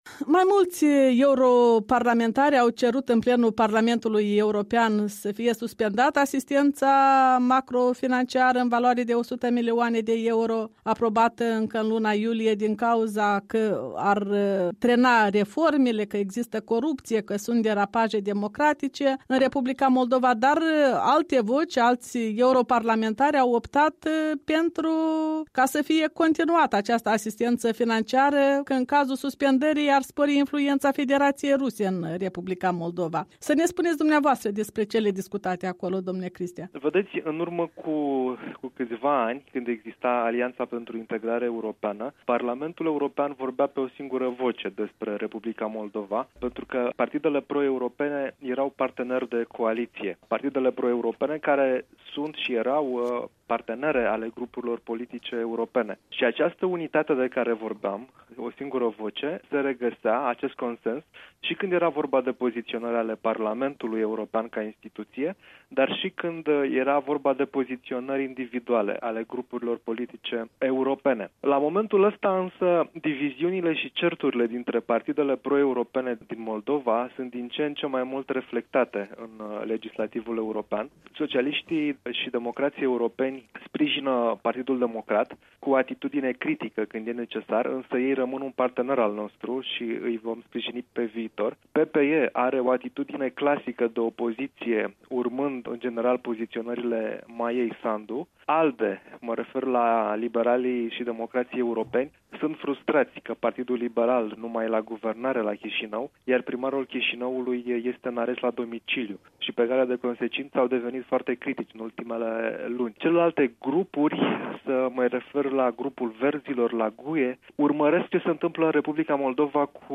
Într-un interviu pentru Europa Liberă, europarlamentarul român constată că diviziunile dintre partidele pro-europene din Moldova sunt din ce în ce mai mult reflectate în legislativul UE.